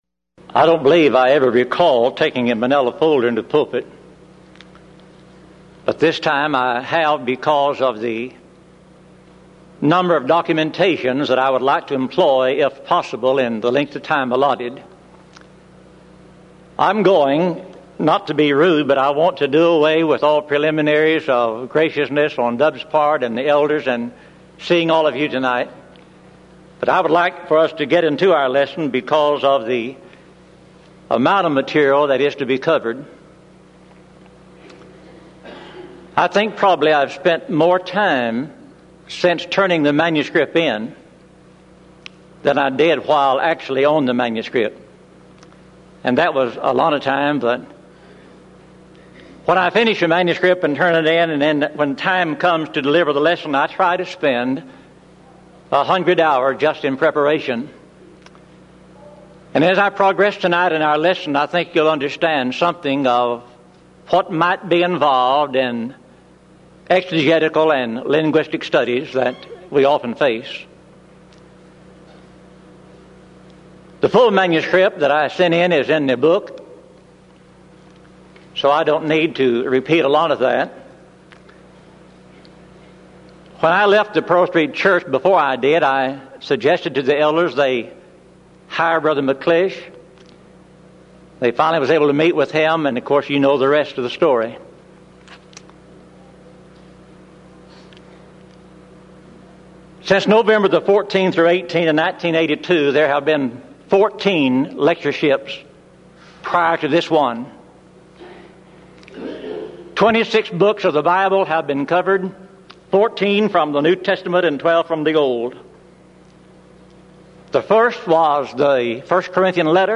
Event: 1996 Denton Lectures Theme/Title: Studies In The Book Of Romans